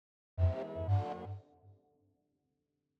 teleport_to_1.wav